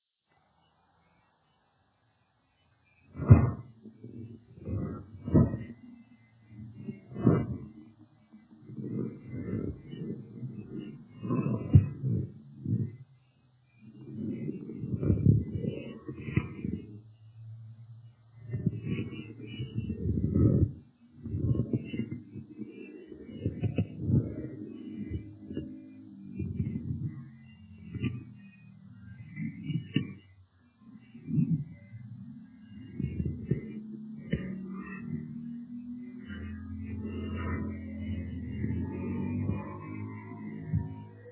描述：免费声音，效果sonidos de sabanas moviendose de manera rapida